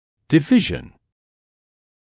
[dɪ’vɪʒn][ди’вижн]отдел, участок; деление, разделение